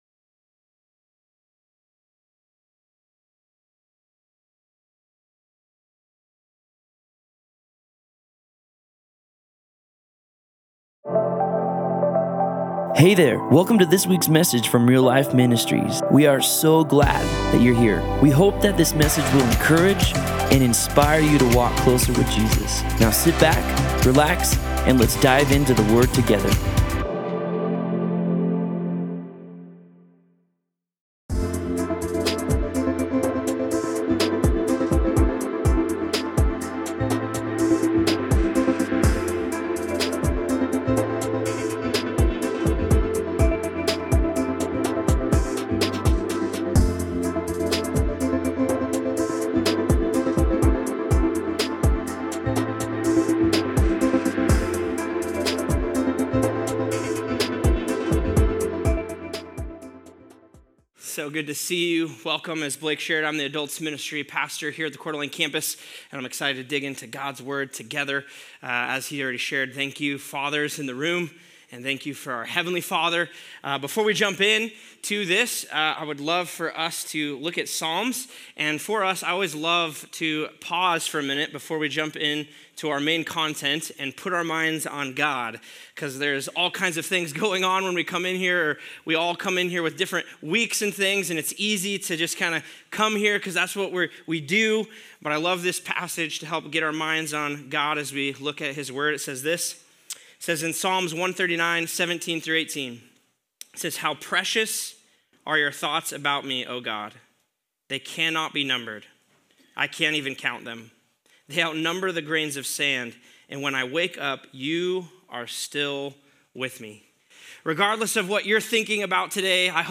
Other Sermon in this Series